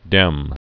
(dĕm)